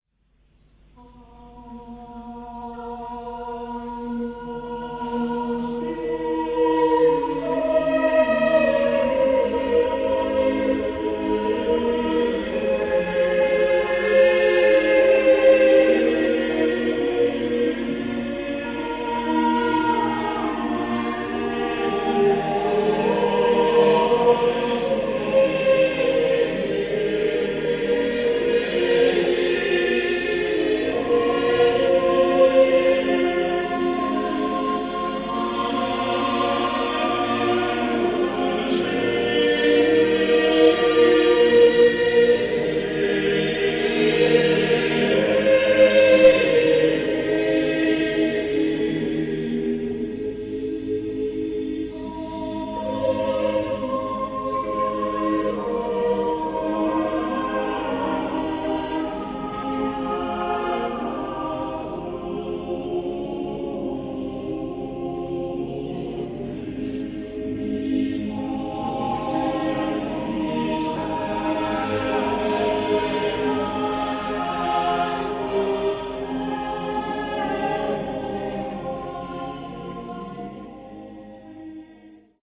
Here are some samples of great choral music, mostly own recordings made during concerts of the choirs named on this site.